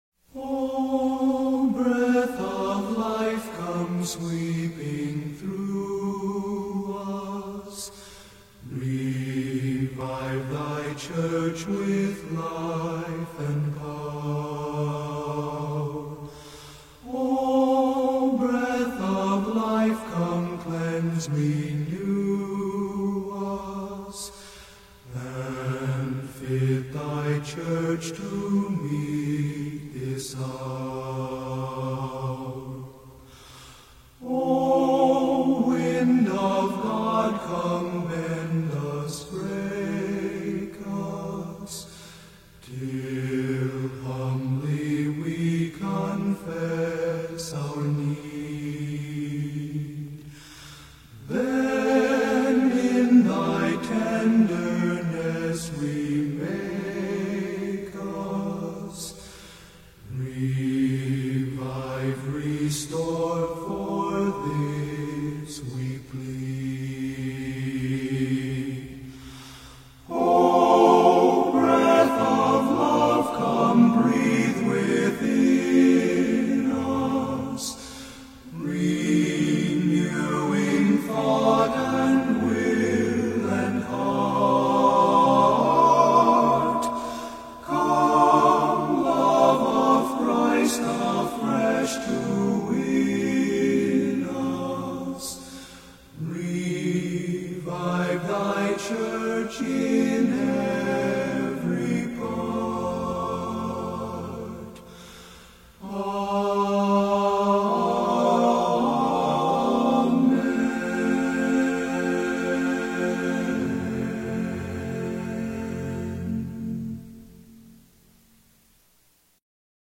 OPENING HYMN